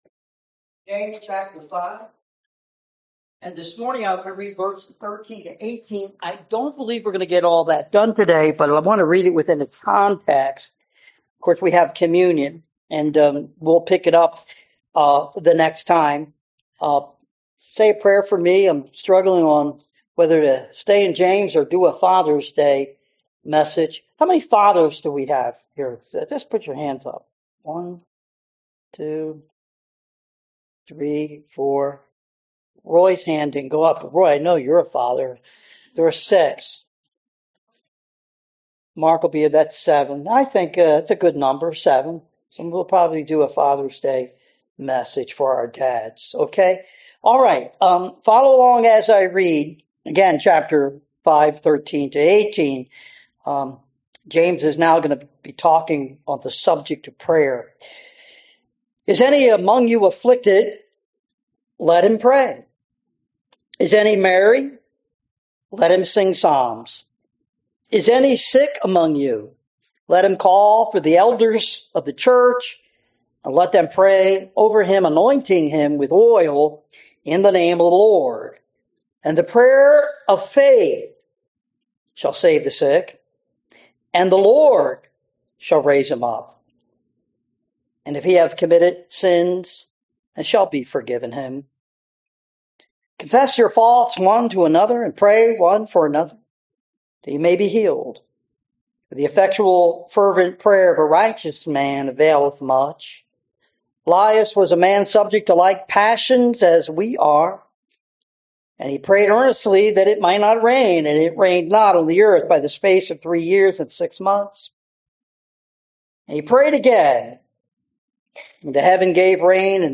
Service Type: Sunday Morning
sermon-June-8-2025.mp3